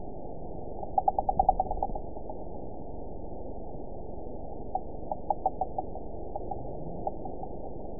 event 911696 date 03/06/22 time 17:30:46 GMT (3 years, 2 months ago) score 8.50 location TSS-AB05 detected by nrw target species NRW annotations +NRW Spectrogram: Frequency (kHz) vs. Time (s) audio not available .wav